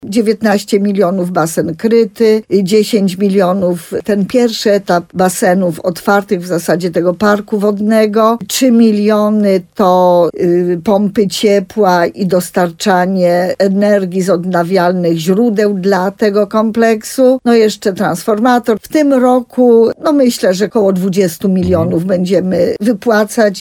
Jak powiedziała w rozmowie Słowo za Słowo w radiu RDN Nowy Sącz wójt gminy Sękowa Małgorzata Małuch, koszt całego kompleksu wodnego może wynieść nawet 40 mln złotych.